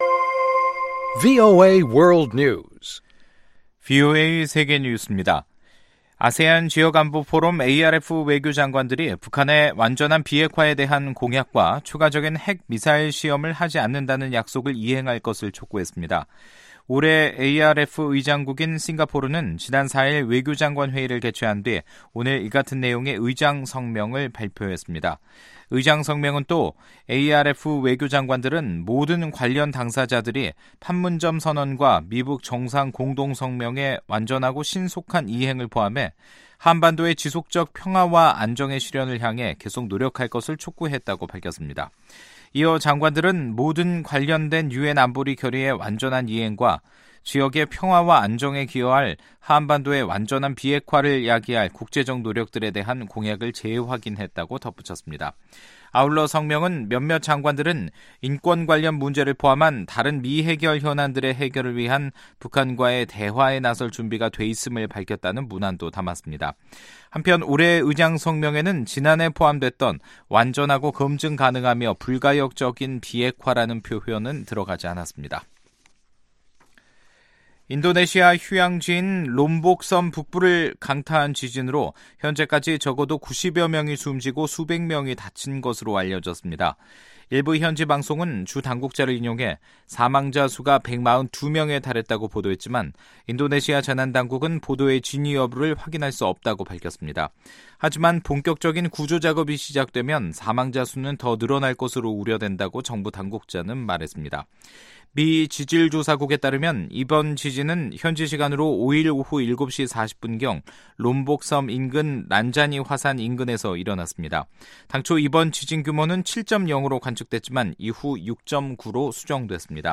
VOA 한국어 간판 뉴스 프로그램 '뉴스 투데이', 2018년 8월 3일 2부 방송입니다. 트럼프 행정부가 북한의 비핵화 약속을 진지하지 않은 것으로 결론 내릴 시점이 올 수 있다고 백악관 국가안보 보좌관이 말했습니다. 북한에 스포츠 장비를 반입하게 해 달라는 국제올림픽위원회의 요청이 미국의 반대로 거부됐다고 유엔 안보리가 공식 확인했습니다.